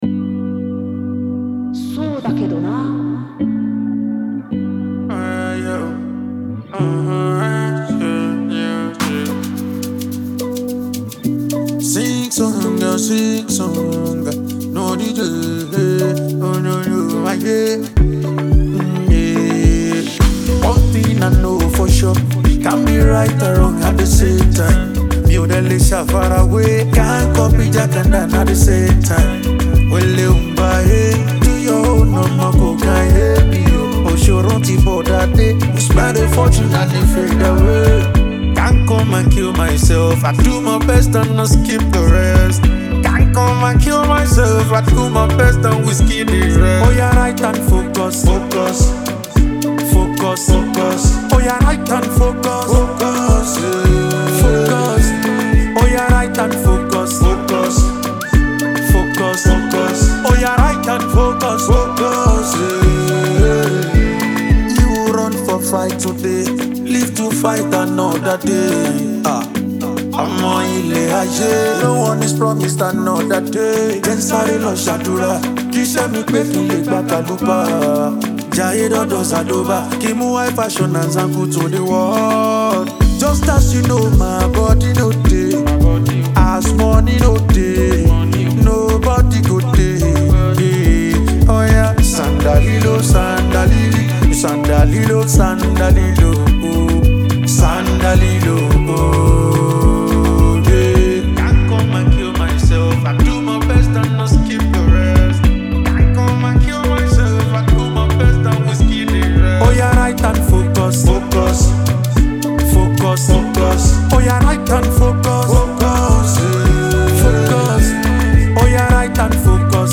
Nigerian-born singer and songwriter
Produced with lush instrumentation